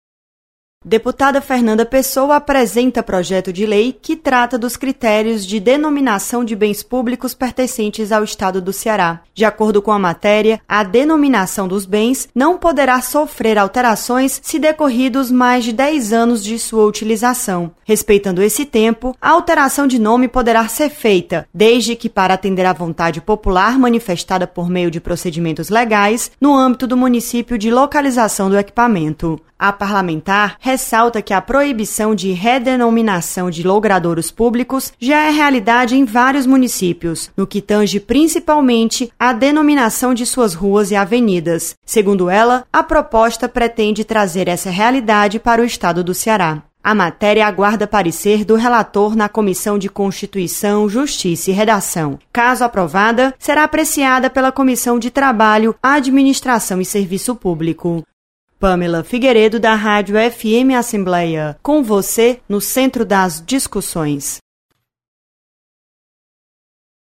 Projeto estabelece critérios para denominação de bens públicos. Repórter